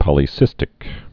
(pŏlē-sĭstĭk)